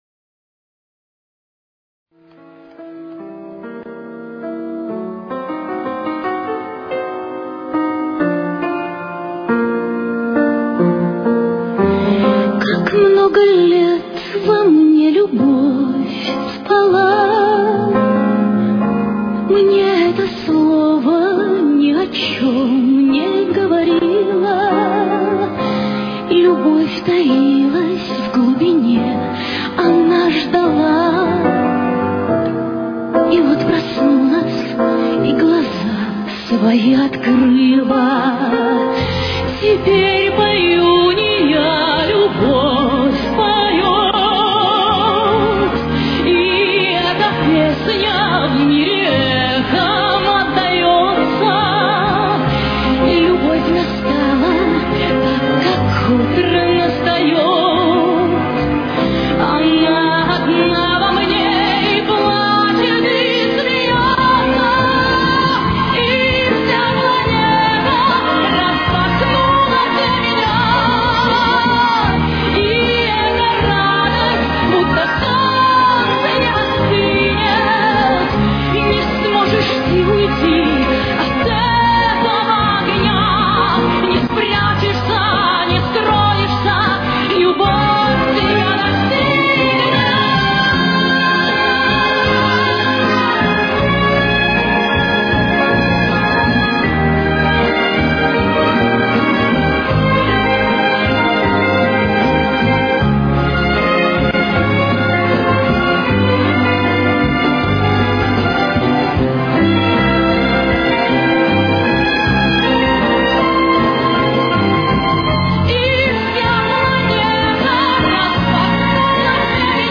с очень низким качеством (16 – 32 кБит/с)
Темп: 80.